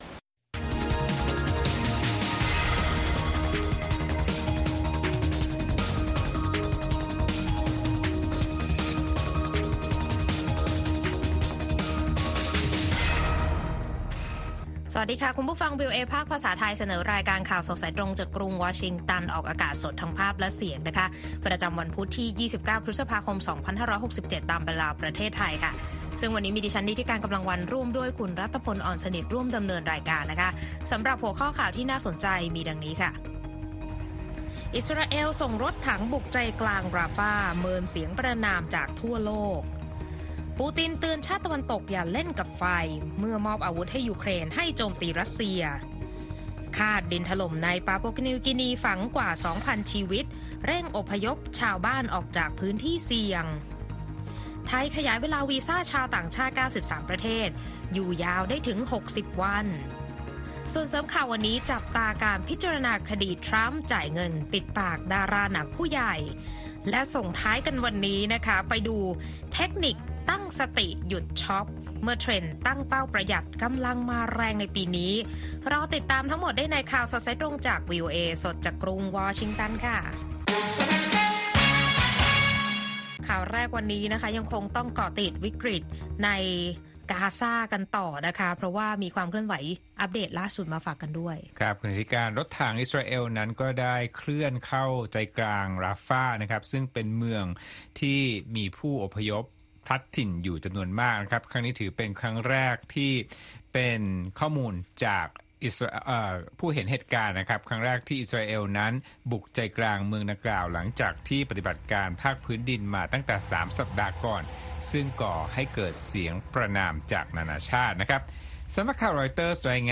ข่าวสดสายตรงจากวีโอเอ ไทย พุธ ที่ 29 พฤษภาคม 2567